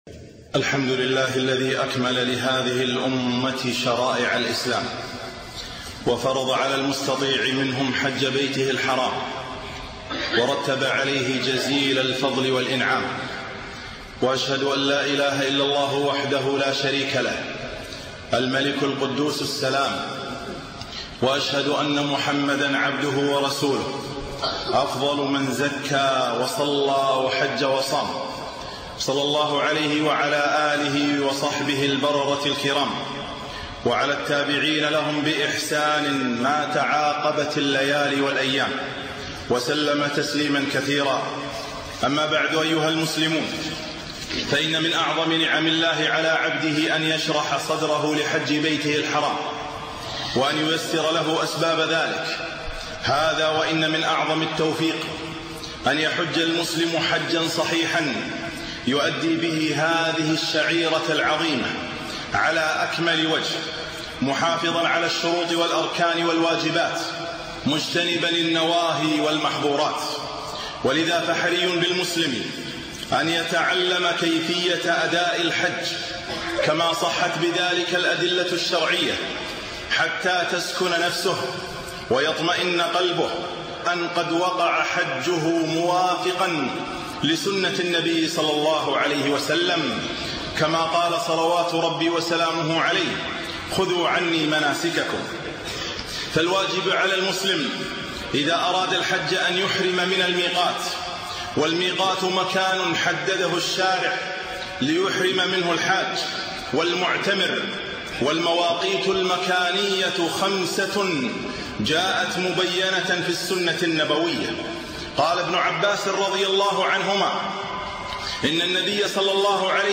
خطبة - تنبيهات للحجاج